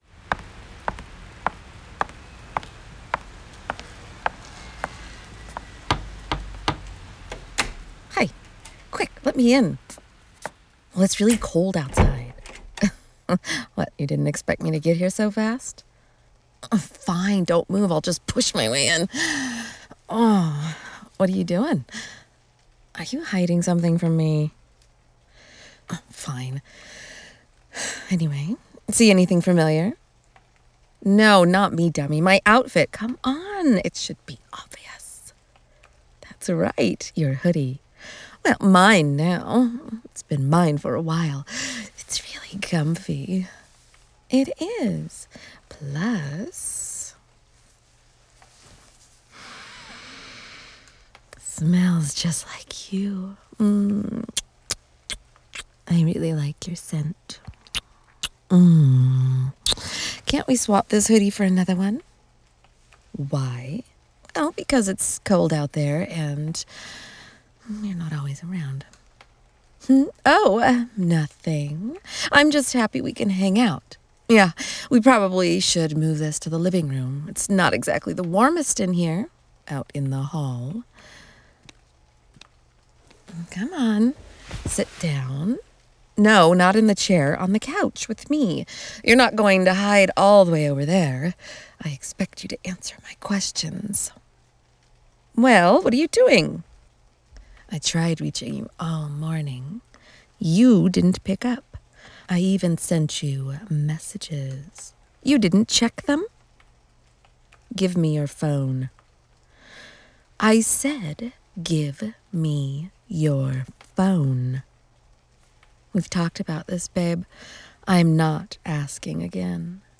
💋 Something a little different in flavor...not a soft...a little rougher around the edges.